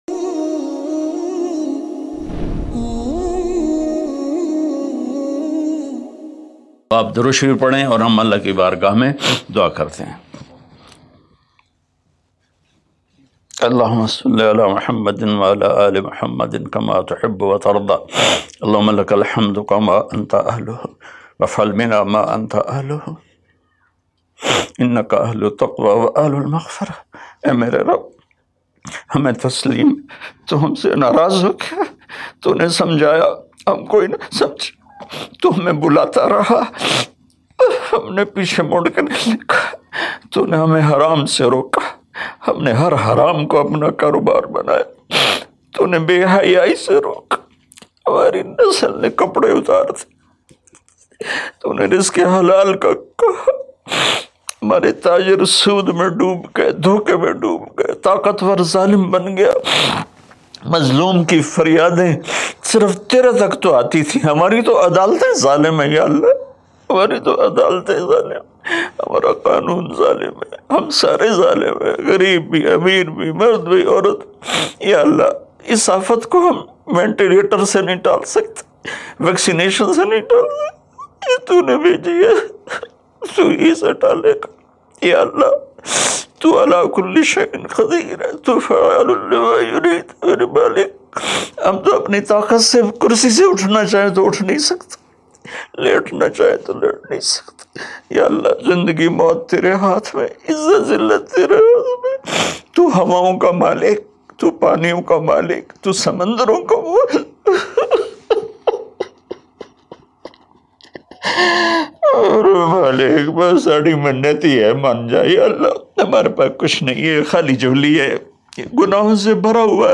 Heart Touching Dua Molana Tariq Jamil Latest MP3 Download
Heart-Touching-Dua.mp3